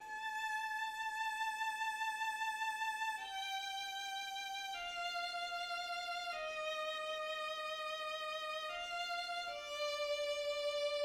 \new staff \relative c''' { \set Staff.midiInstrument = #"violin" \key d \minor \time 4/4 \tempo "Moderato" \tempo 8 = 76 a2(_\p) (g4 f) es4. (f8 d4) }